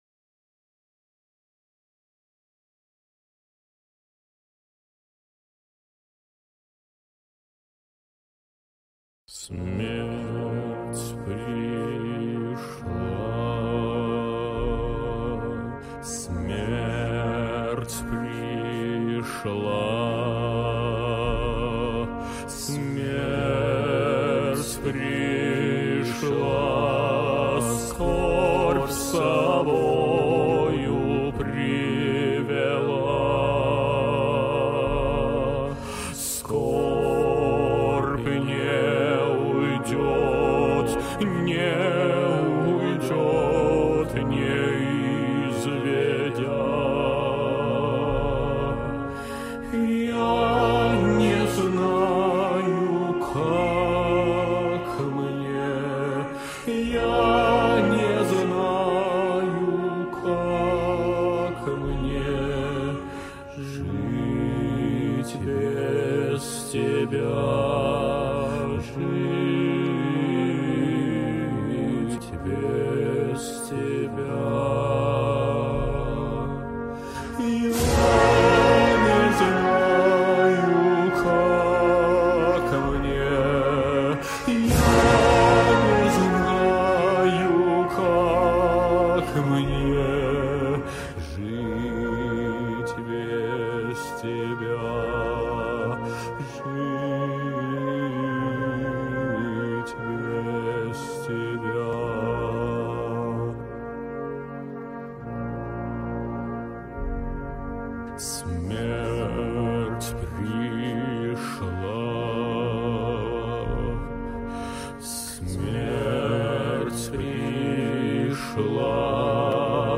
Музыка для похорон с текстом